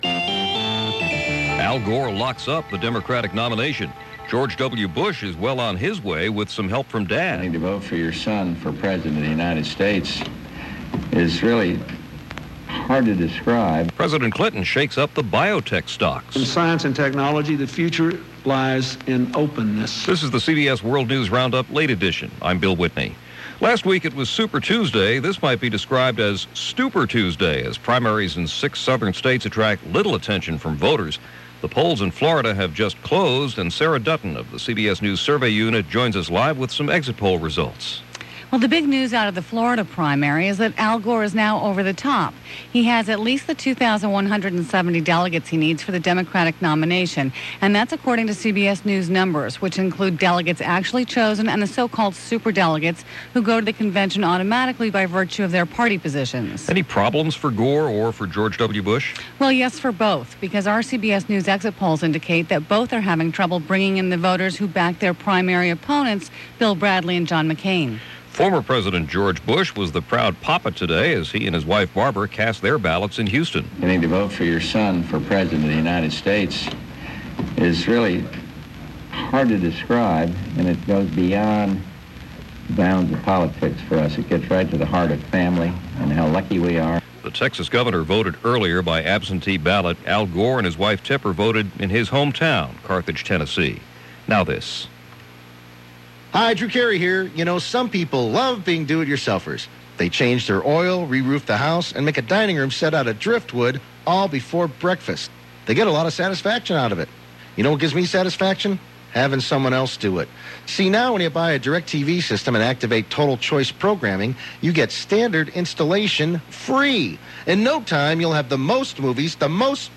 And that’s just a small slice of what went on, this March 14th in 2000, as reported by The CBS World News Roundup: Late Edition.